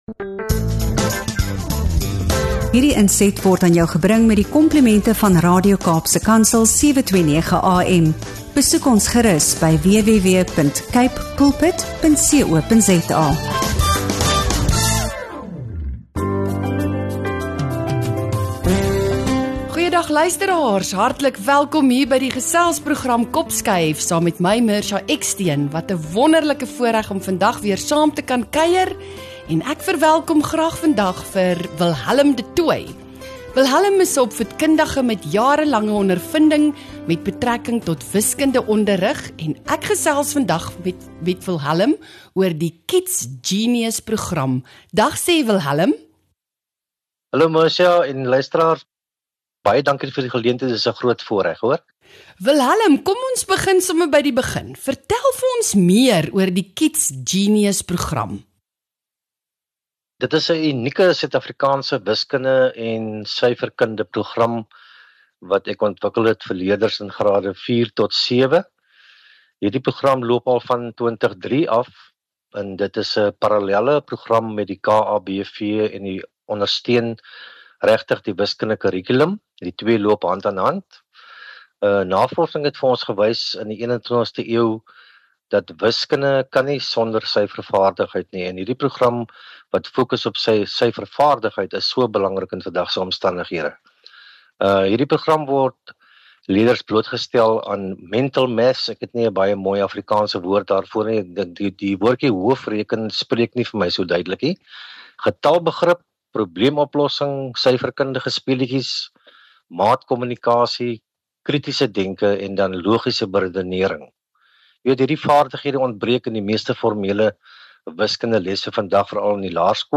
Die gesprek fokus op die Kids Genius-program, wat spesifiek gemik is op leerders in grade 4 tot 7.